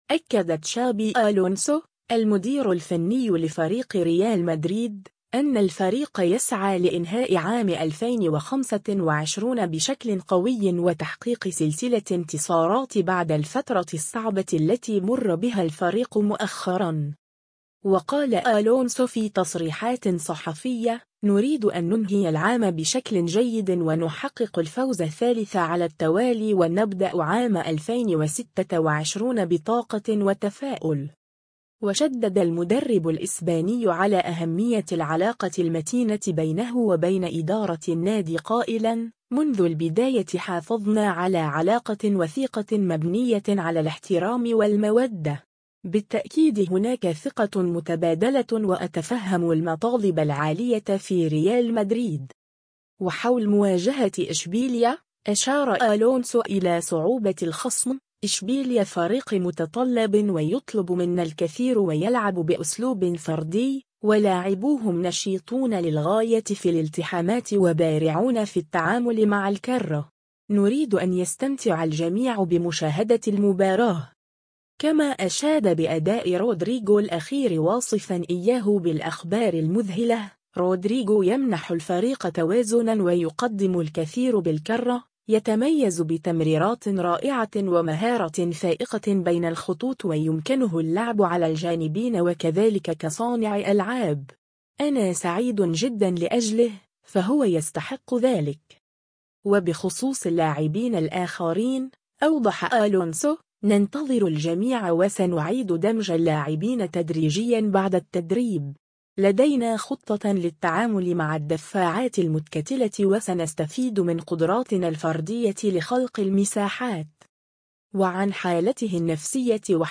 و قال ألونسو فى تصريحات صحفية : “نريد أن ننهي العام بشكل جيد و نحقق الفوز الثالث على التوالي و نبدأ عام 2026 بطاقة وتفاؤل”.